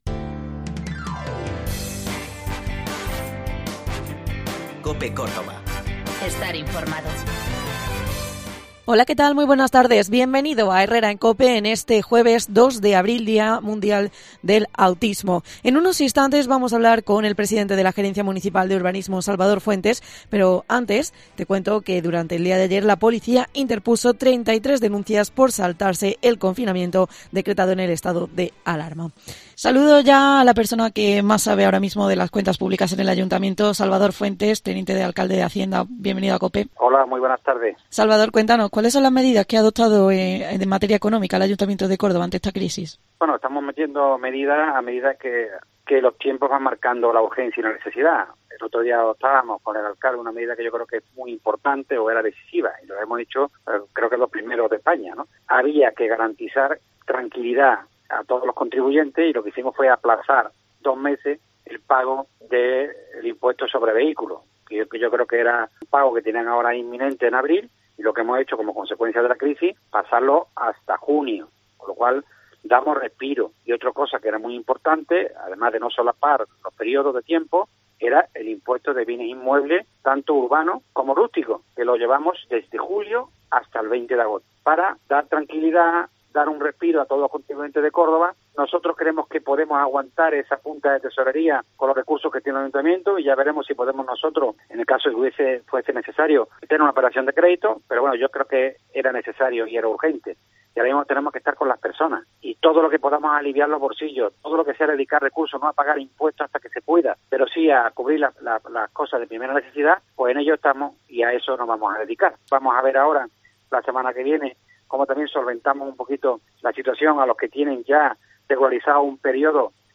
Por los micrófonos de COPE ha pasado el Teniente de Alcalde delegado de Hacienda, Vivienda y Urbanismo, Salvador Fuentes, quien ha explicado las diferentes medidas que ha adoptado el gobierno local ante esta crisis del COVID-19.